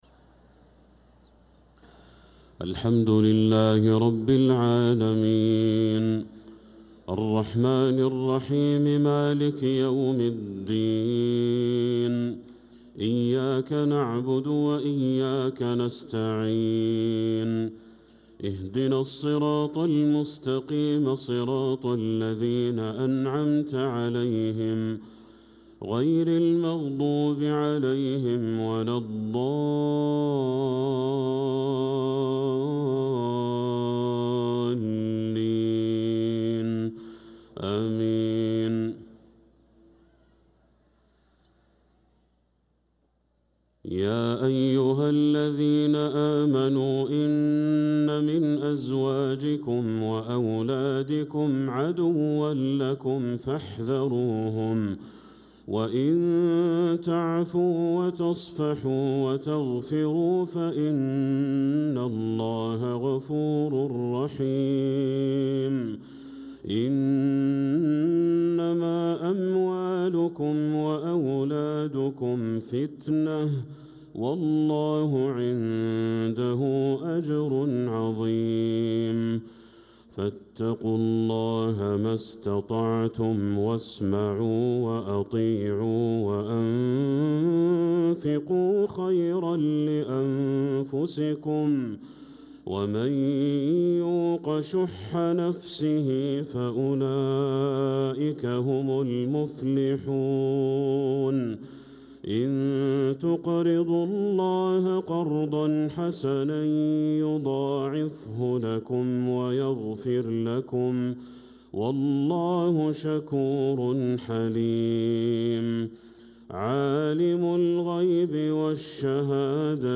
صلاة المغرب للقارئ بدر التركي 22 رجب 1446 هـ